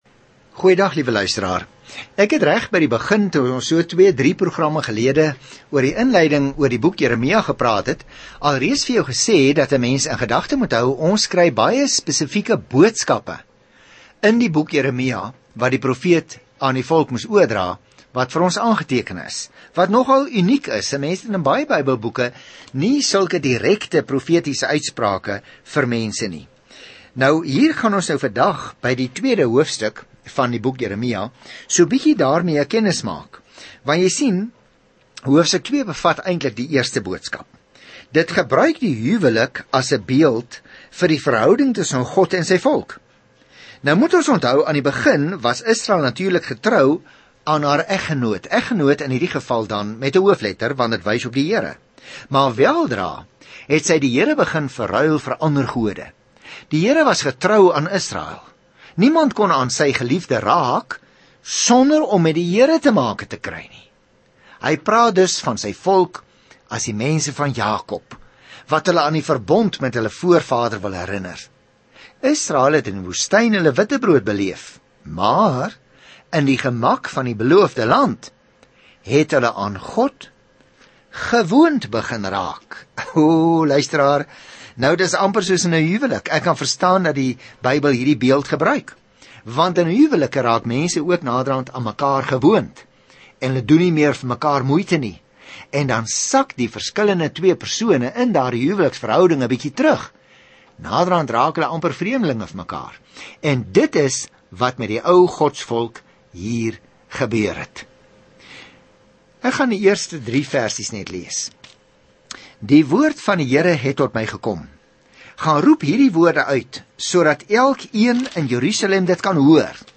Reis daagliks deur Jeremia terwyl jy na die oudiostudie luister en uitgesoekte verse uit God se woord lees.